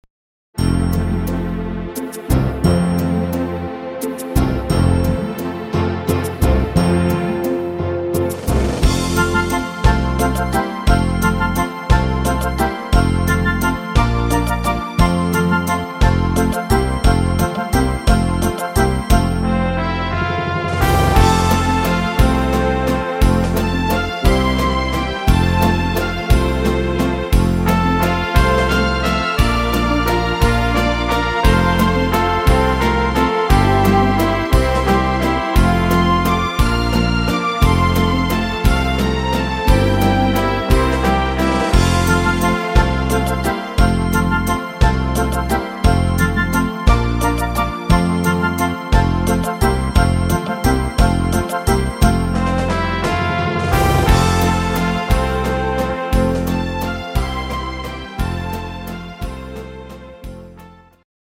Wiener Walzer Version